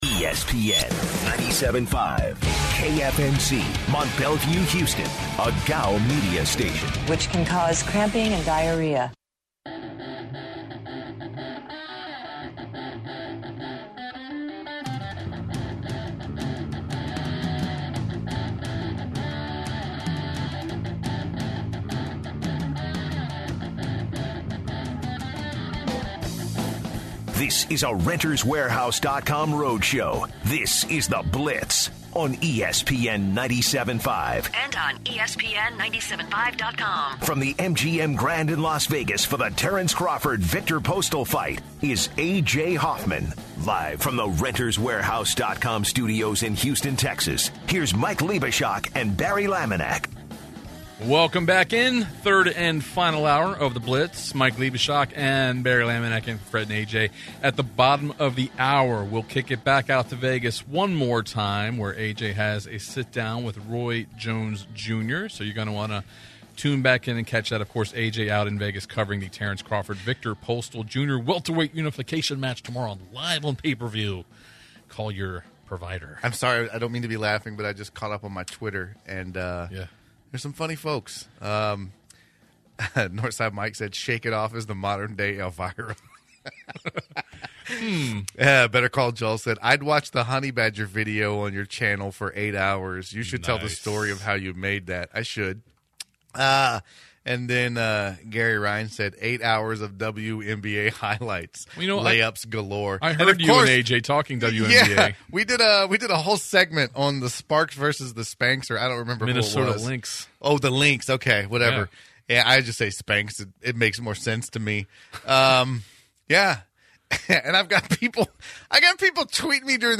interviews boxer Roy Jones Jr.